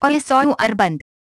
AXEFIAudio_hi_VoiceOverOff.wav